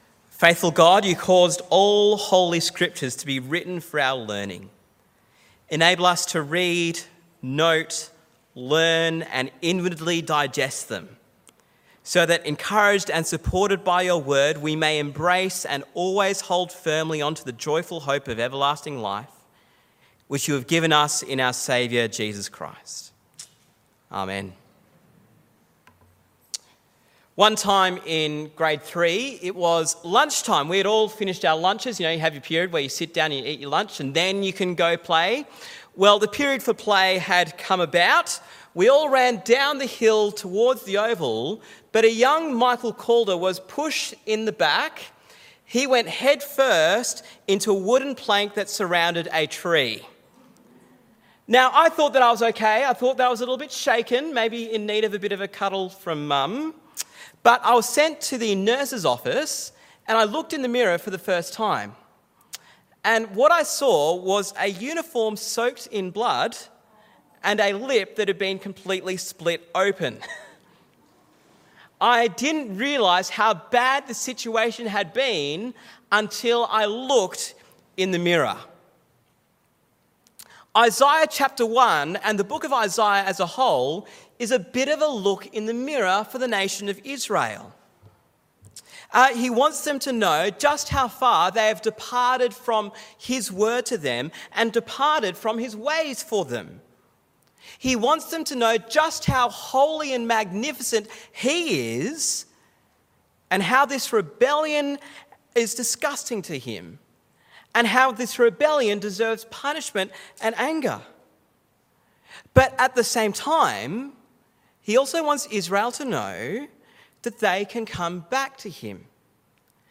Listen to the sermon on Isaiah 1 in our Isaiah series.